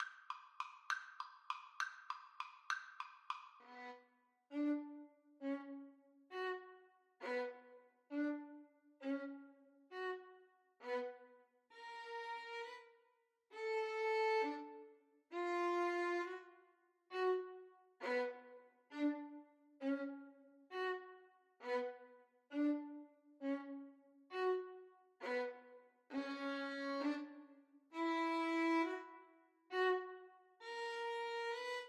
Violin 1Violin 2
Moderato
6/8 (View more 6/8 Music)
Classical (View more Classical Violin Duet Music)